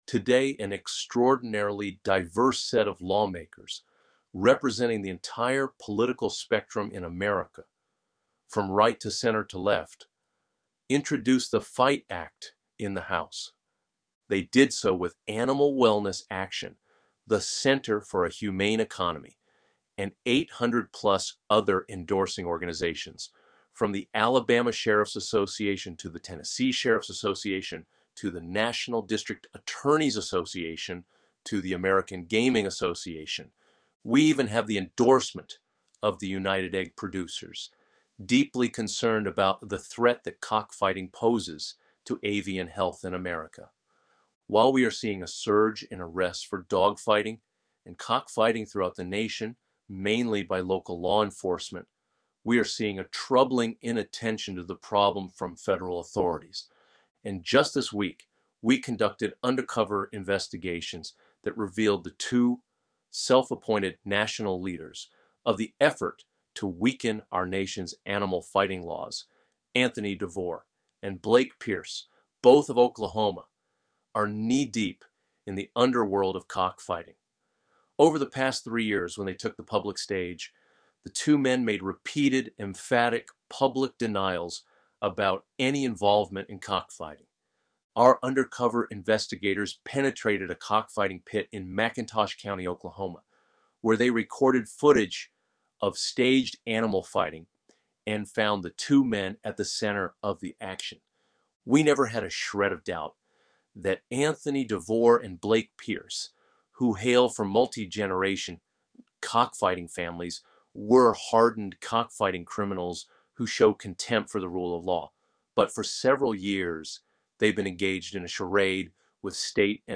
You can listen to an AI-generated reading of this story here: